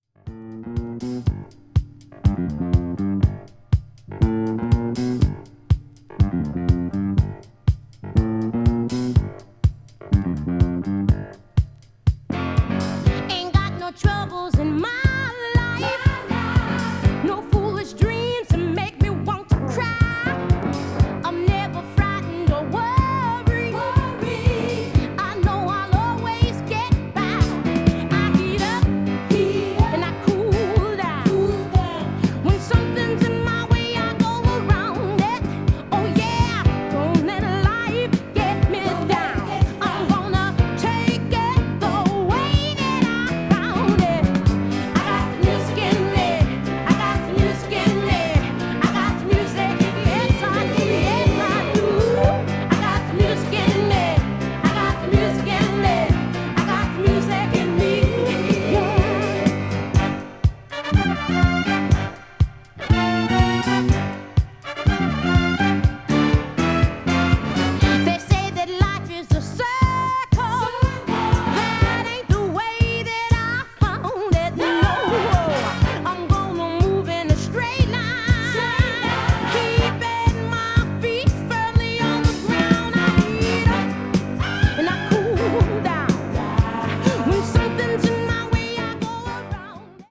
media : EX/EX(わずかにチリノイズが入る箇所あり)
ballad   disco related   funk   r&b   soul